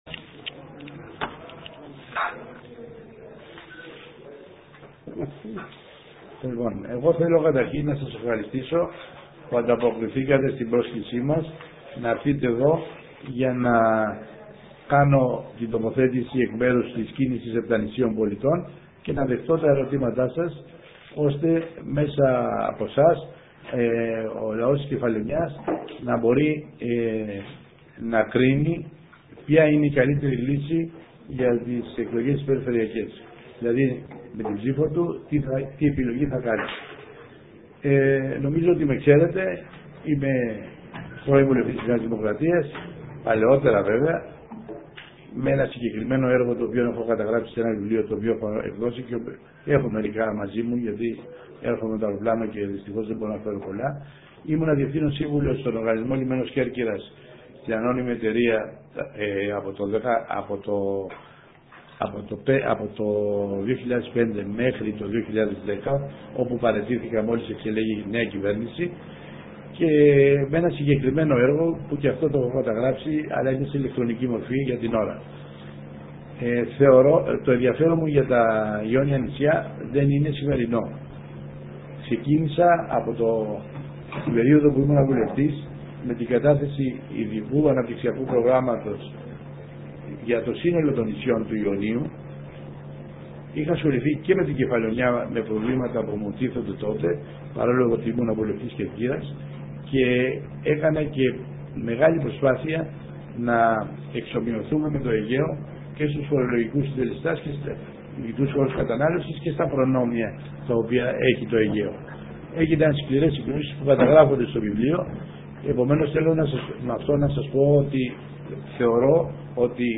συνέντευξη τύπου